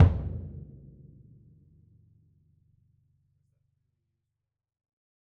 BDrumNewhit_v6_rr1_Sum.wav